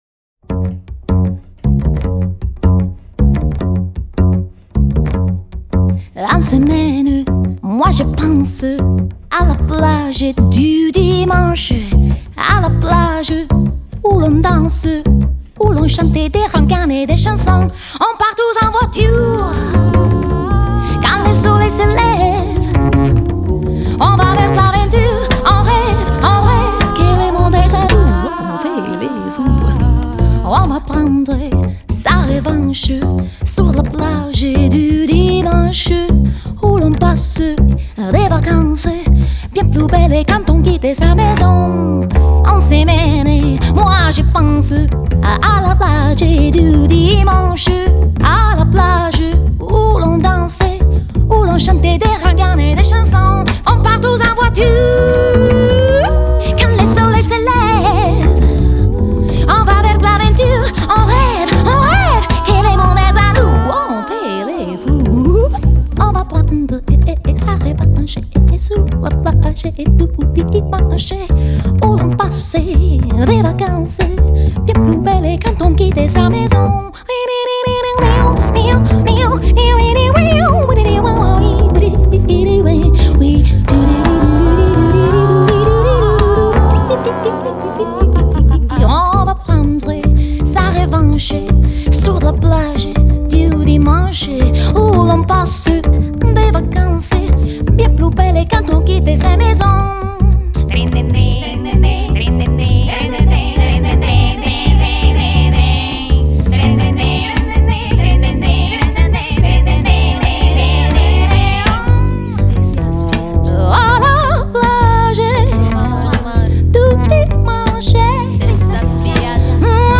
Жанр: Jazz/Pop.